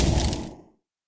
battlemage_hit2.wav